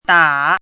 chinese-voice - 汉字语音库
da3.mp3